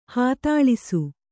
♪ hātāḷisu